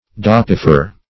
Search Result for " dapifer" : The Collaborative International Dictionary of English v.0.48: Dapifer \Dap"i*fer\, n. [L., daps a feast + ferre to bear.] One who brings meat to the table; hence, in some countries, the official title of the grand master or steward of the king's or a nobleman's household.